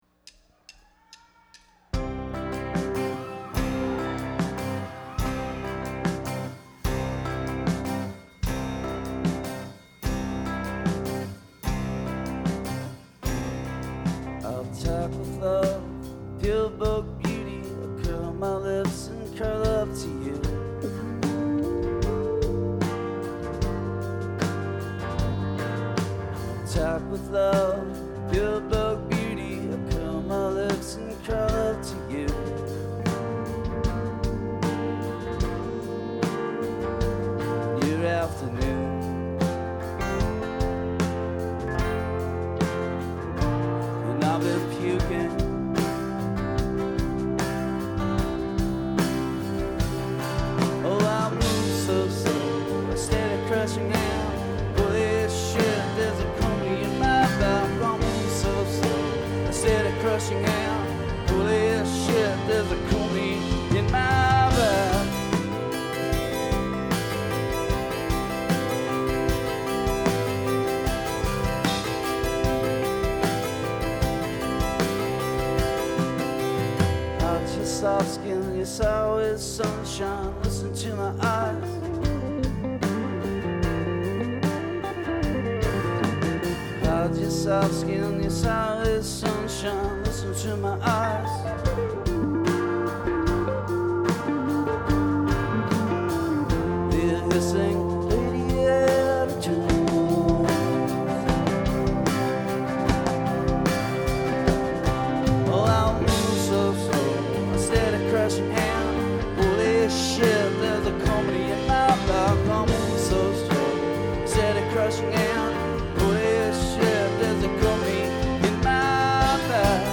registrato a Montreal il 28 Giugno 2005